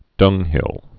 (dŭnghĭl)